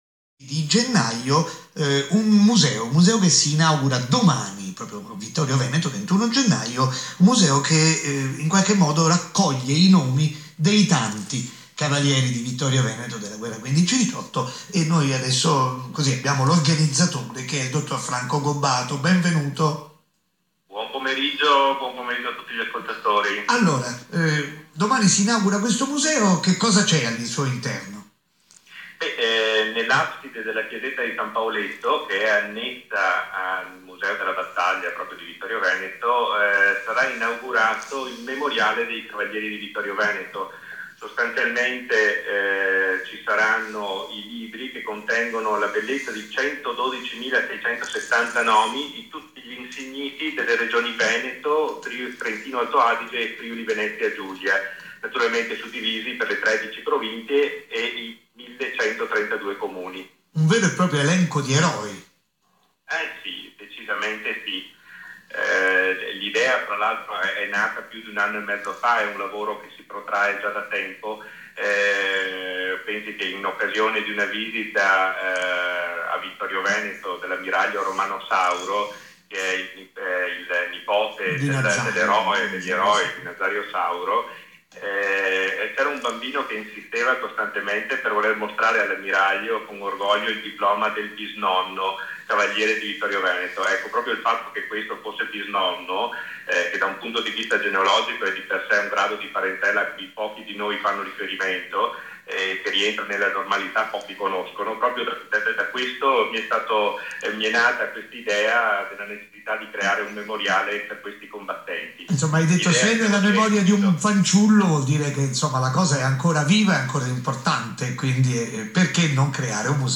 Intervista su RAI - ISORADIO del 20 gennaio 2017